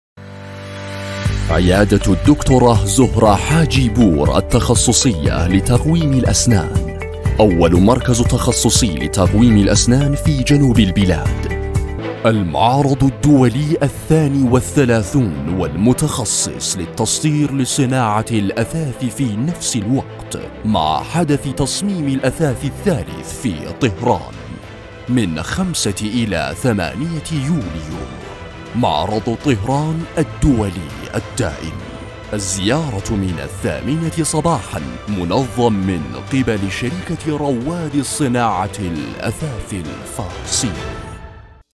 Male
Adult
Commercial